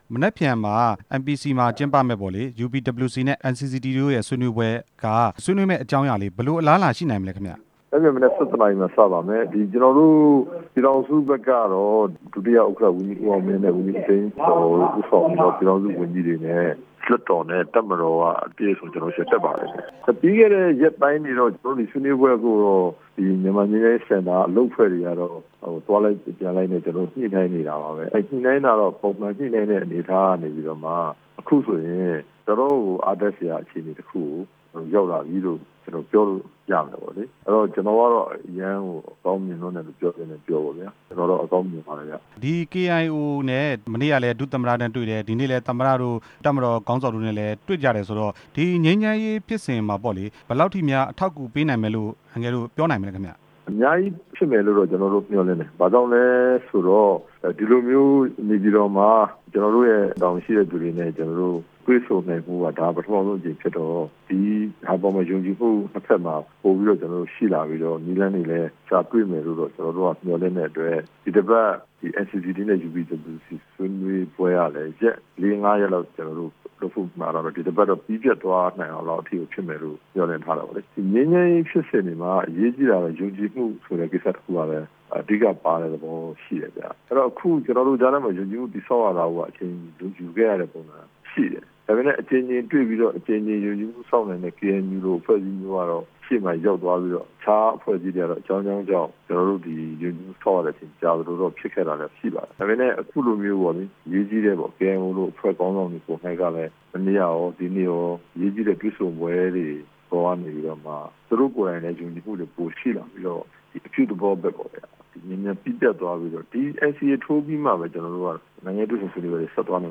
UPWC နဲ့ NCCT တို့ ရန်ကုန်မြို့မှာ ဆွေးနွေးမယ့်အကြောင်း မေးမြန်းချက်